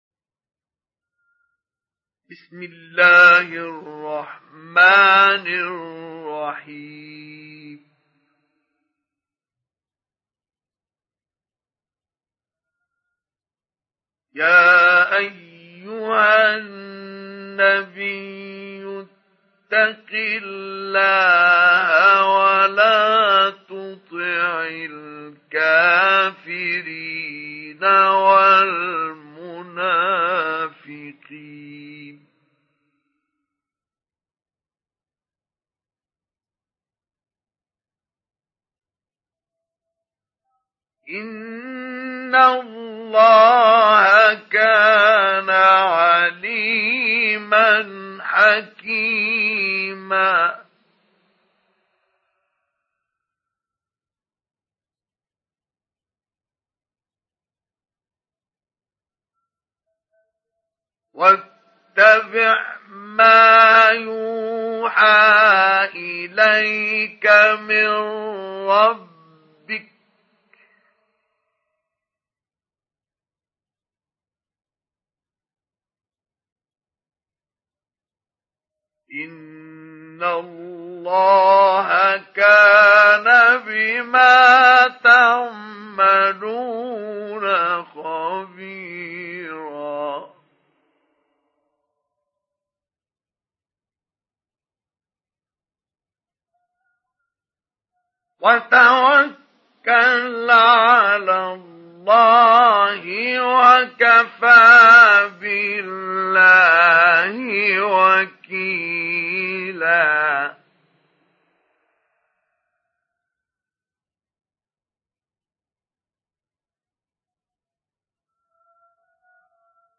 سُورَةُ الأَحۡزَابِ بصوت الشيخ مصطفى اسماعيل